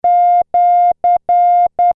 和文符号wabun
和文の文字をクリックすると和文符号が再生されます。